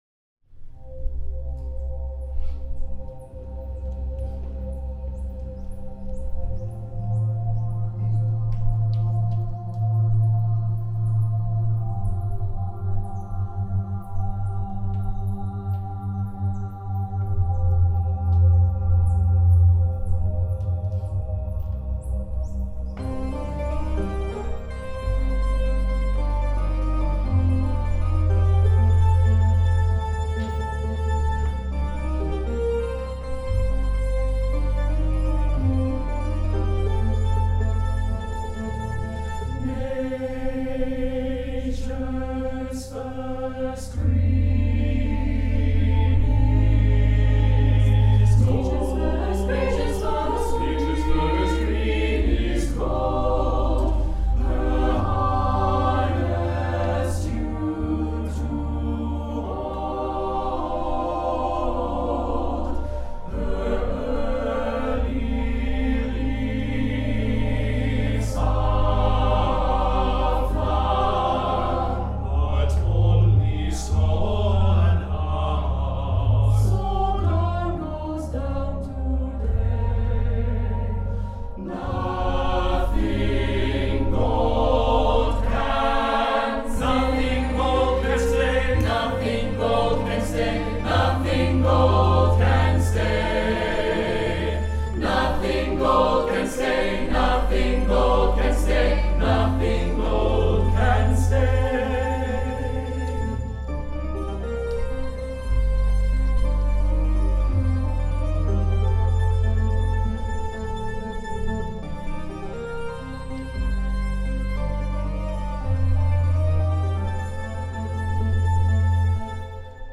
SAB + Synth (opt. Piano) 3’30”
SAB, Synth, Piano